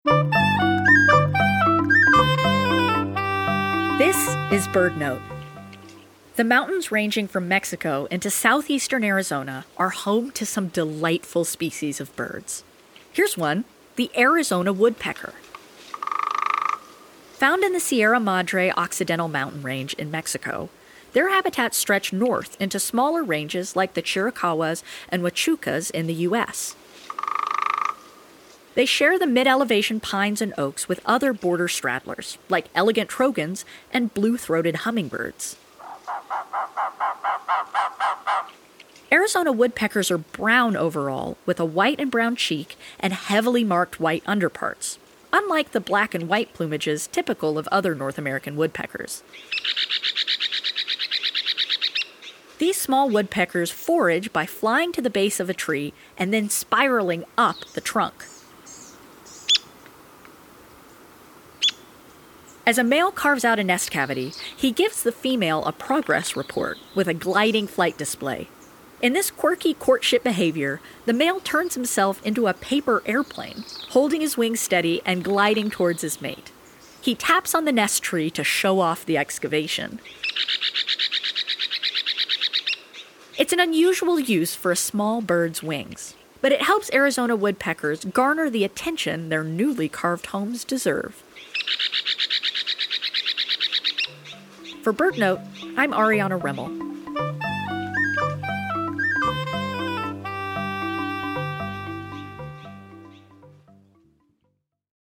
BirdNote is sponsored locally by Chirp Nature Center and airs live every day at 4 p.m. on KBHR 93.3 FM.